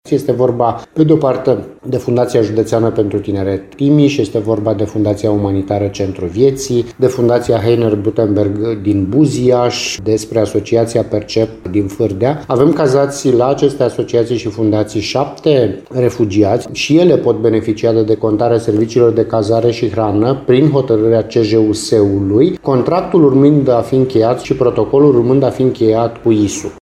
În Timiș exista cinci astfel de organizații care au pregătite 129 de locuri pentru persoanele care ajung din Ucraina, mai spune subprefectul Sorin Ionescu: